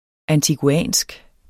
antiguansk adjektiv Bøjning -, -e Udtale [ antiguˈæˀnsg ] Betydninger fra den caribiske østat Antigua og Barbuda; vedr.